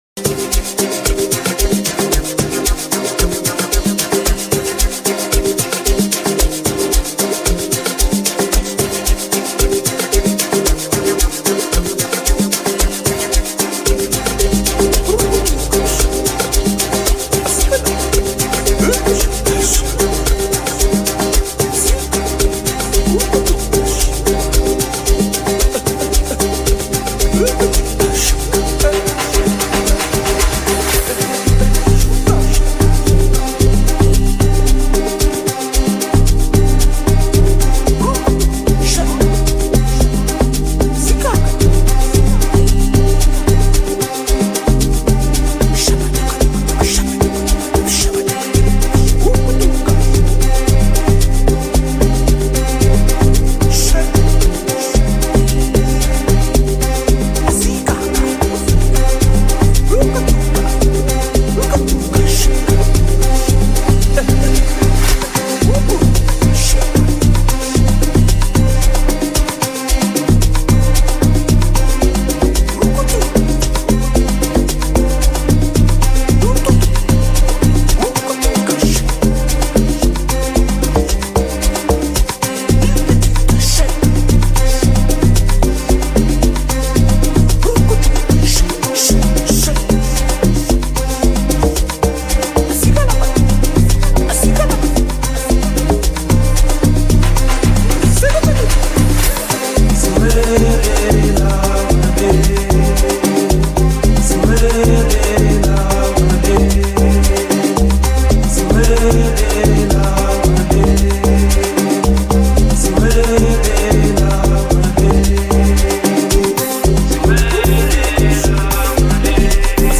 Find more Amapiano Songs on Amapiano Updates .